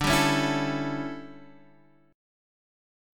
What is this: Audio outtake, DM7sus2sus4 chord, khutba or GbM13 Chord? DM7sus2sus4 chord